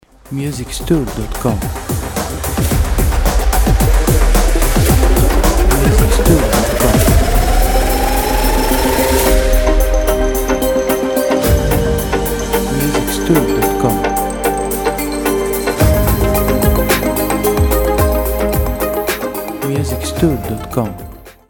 a smooth blend of deep techno and ambient electronic sounds.
With its hypnotic beats and space-inspired textures
• Type : Instrumental
• Bpm : Allegro
• Genre : Riff Music / Techno